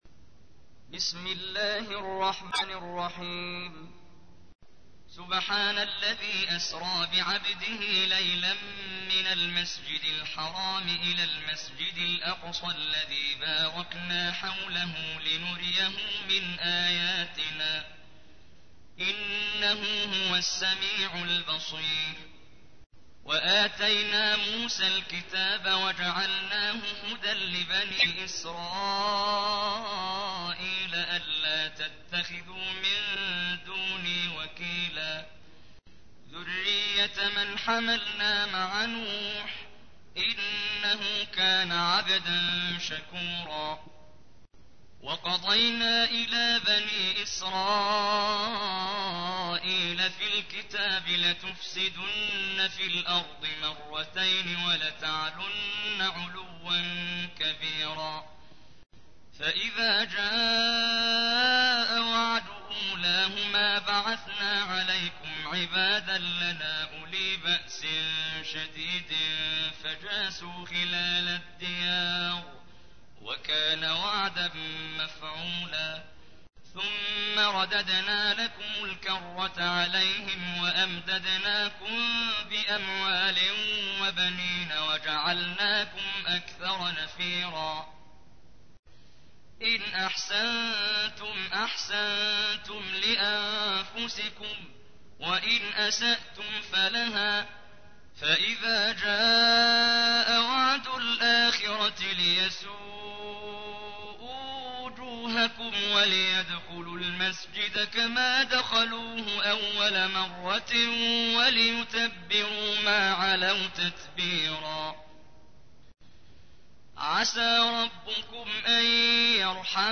تحميل : 17. سورة الإسراء / القارئ محمد جبريل / القرآن الكريم / موقع يا حسين